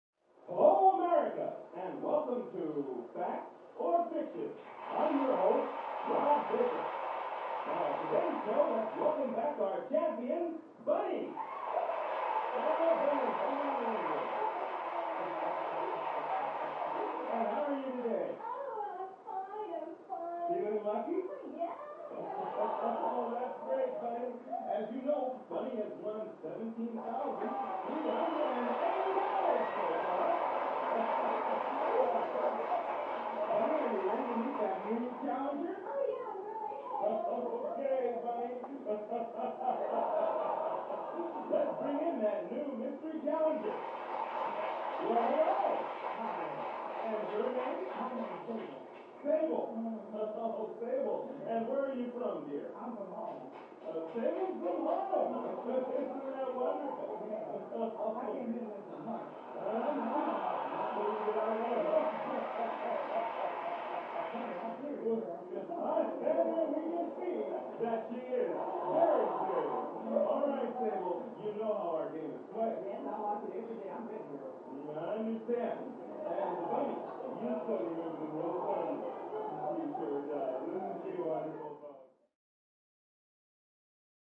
Television; Full Game Show With Announcer, Crowd And Players. From Next Room.